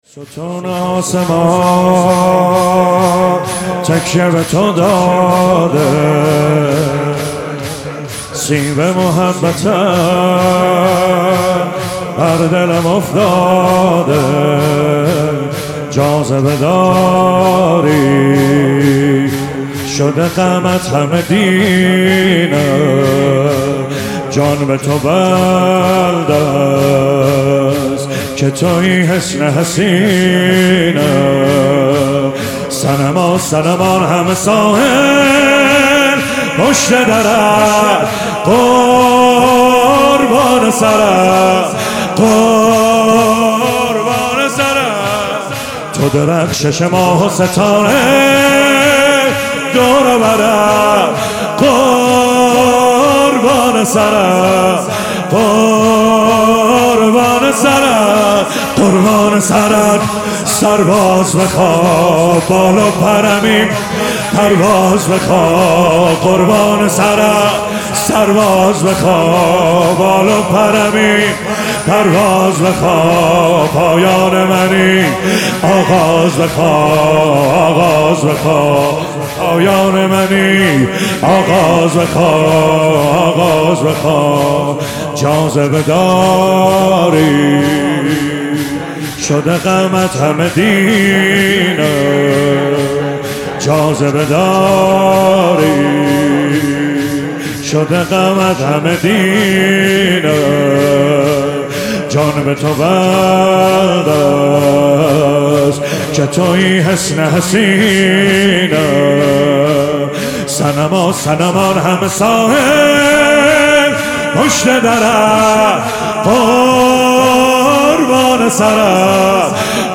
فاطمیه 97 - 30 دی - تهران - شور - ستون آسمان تکیه به تو داده
فاطمیه 97